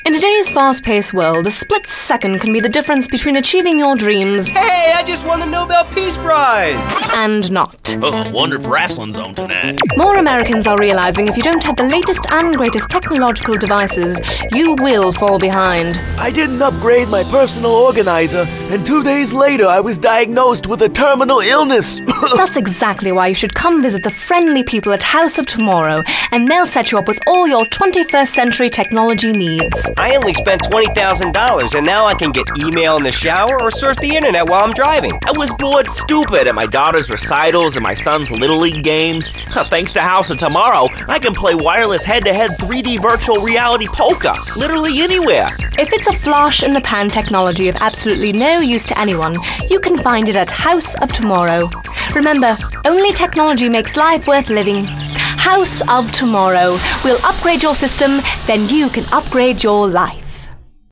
[[Category:Audio ads]]